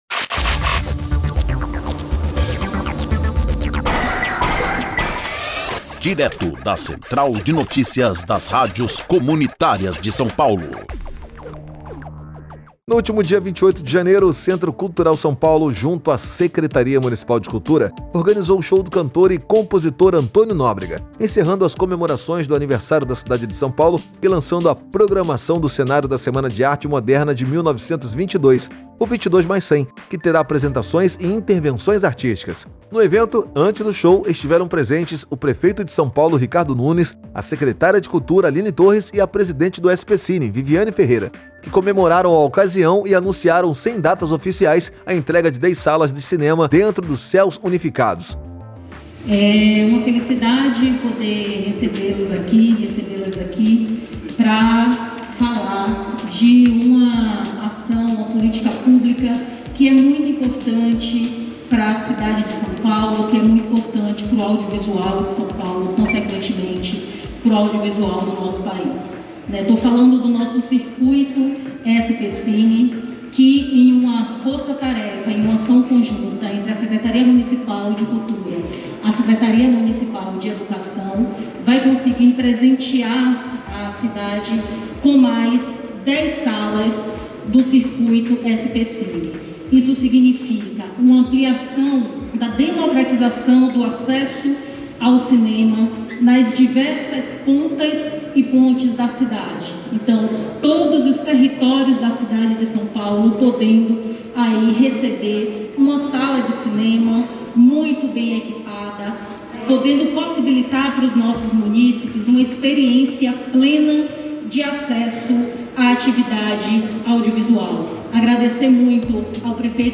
Informativo: CENTRO CULTURAL SÃO PAULO: O MÚSICO NORDESTINO ANTÔNIO NÓBREGA ENCERROU AS COMEMORAÇÕES DO ANIVERSÁRIO DE 468 ANOS DA CIDADE DE SÃO PAULO